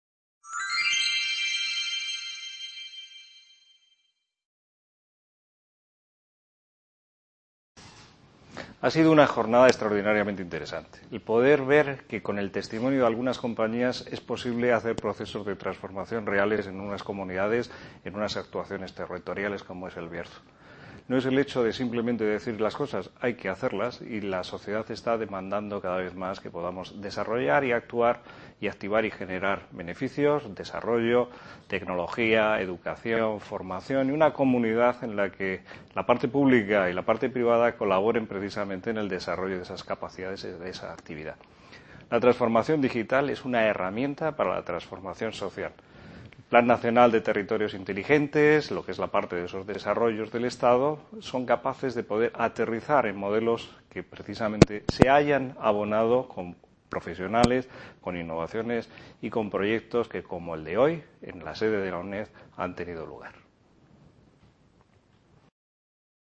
VI Edición del Festival Villar de los Mundos - El PASADO de LOS BARRIOS y el FUTURO de EL BIERZO
Video Clase